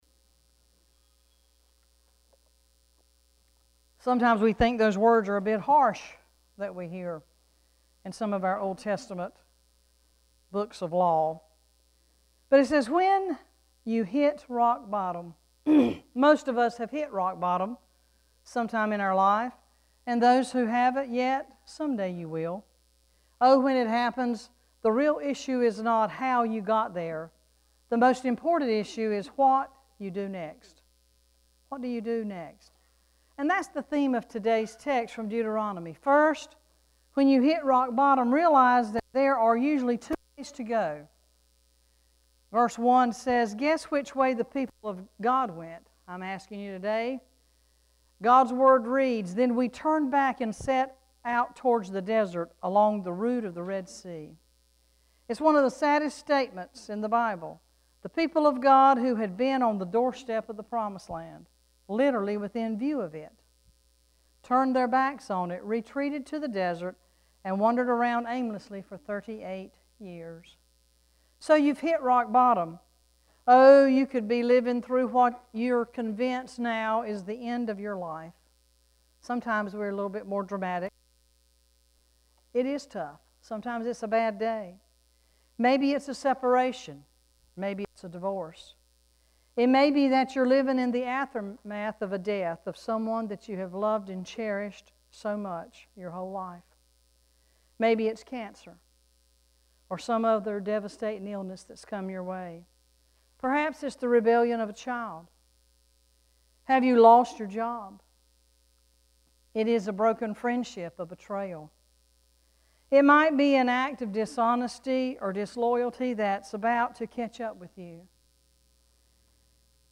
4-2-sermon.mp3